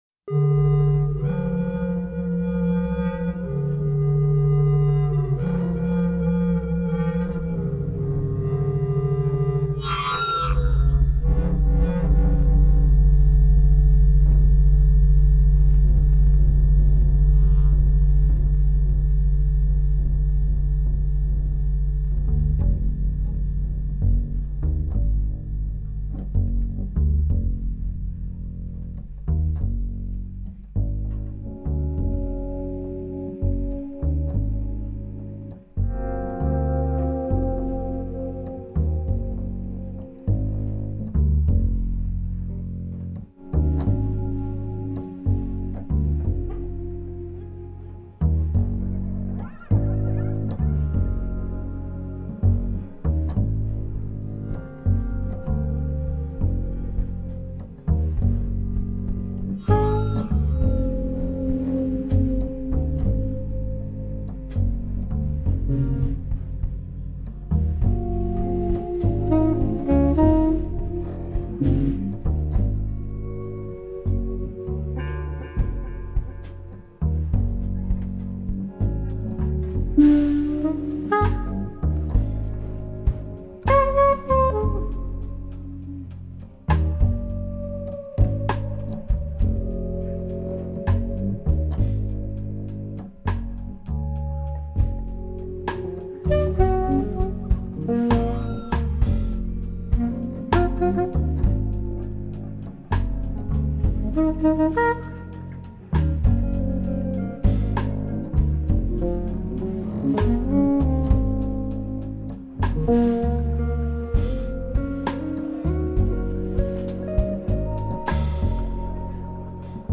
Saxophones /Sampling
Guitar
keyboards
Acoustic bass
Electric bass